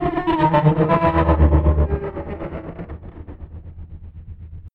恐龙龙的咆哮
描述：恐龙或龙的声音
标签： 生物 Trex公司 动物 咆哮 恐龙 龙吼 恐怖 恐龙 Trex公司 怪物 咆哮 吓人
声道立体声